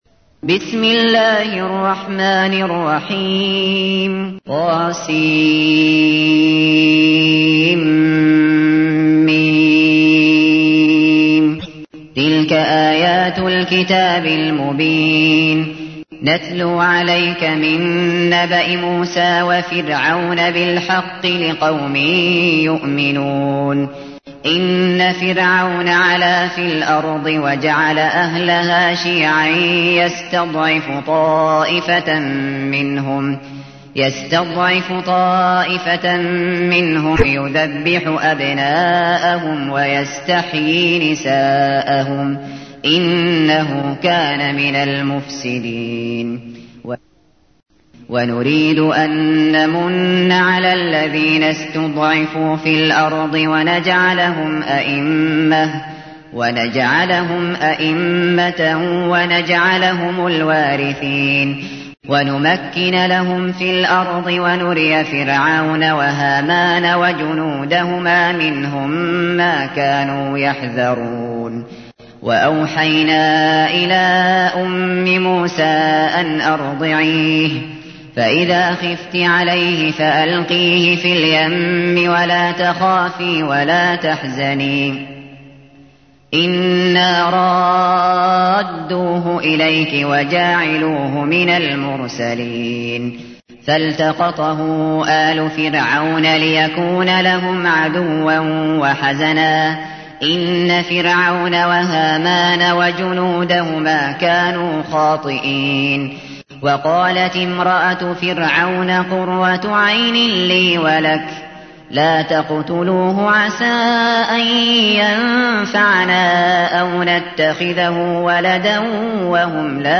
تحميل : 28. سورة القصص / القارئ الشاطري / القرآن الكريم / موقع يا حسين